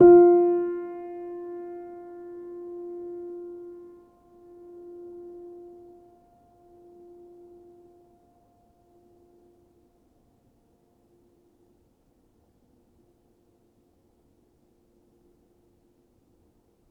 healing-soundscapes/Sound Banks/HSS_OP_Pack/Upright Piano/Player_dyn2_rr1_022.wav at main